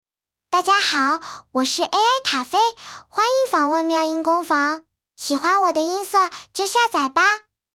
萝莉音 RVC模型